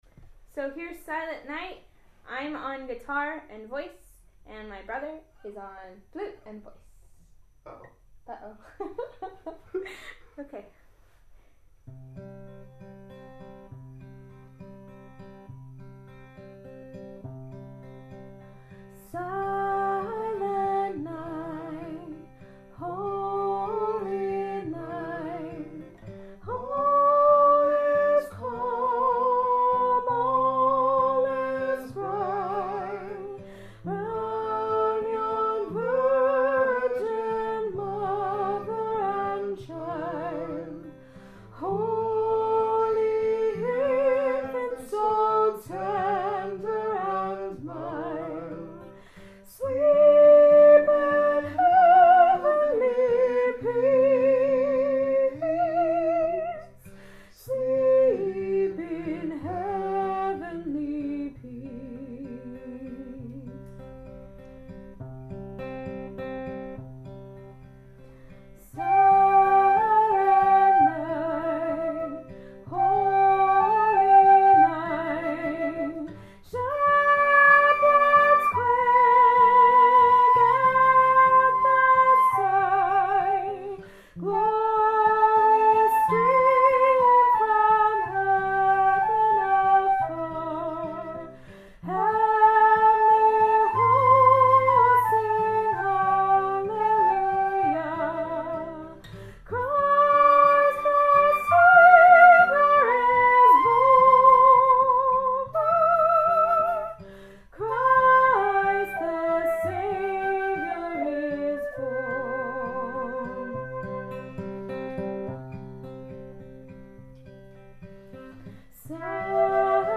Here’s from our rehearsal tonight.
You’ll have to turn your volume down a bit or you’ll get some white noise.
guitar
flute